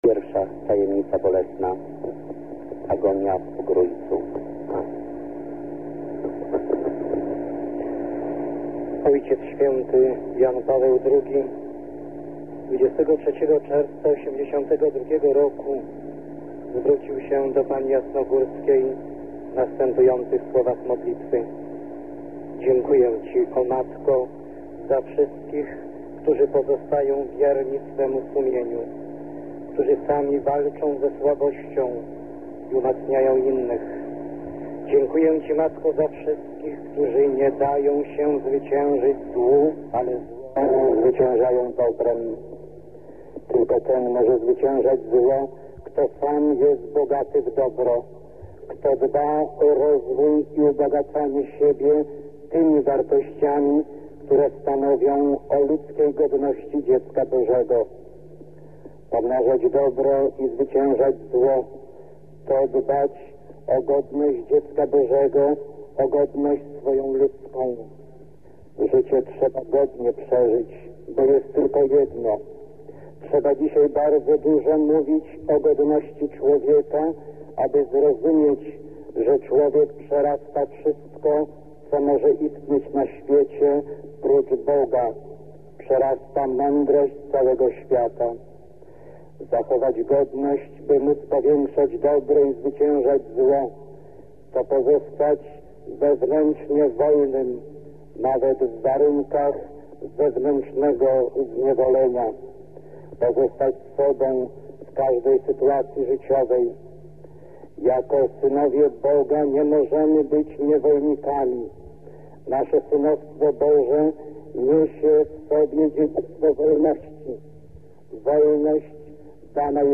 19 października 1984 o godz. 18, w bydgoskim kościele Braci Męczenników ks. Jerzy Popiełuszko odprawił nabożeństwo różańcowe.
Archiwalne nagranie z mową ks. Jerzego